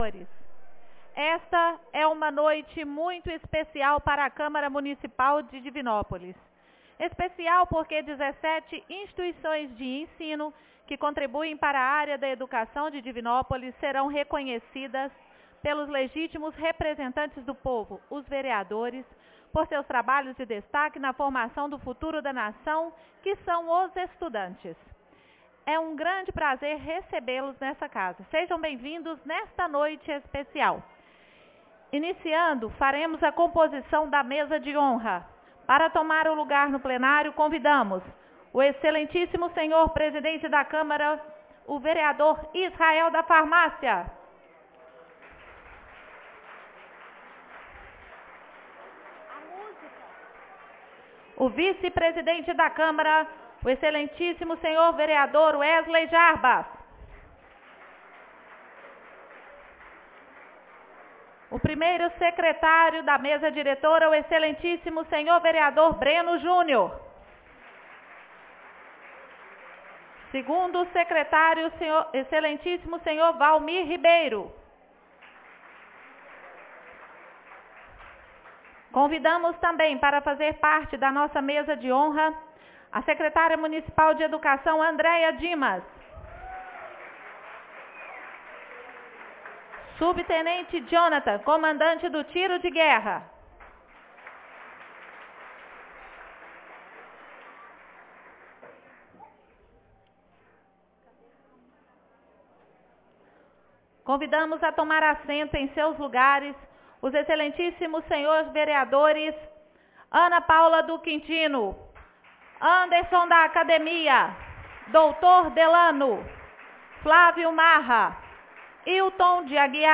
3ª Reunião Especial entrega do Título Escola Destaque 2025